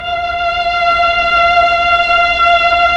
STR_TrnVlnF_5.wav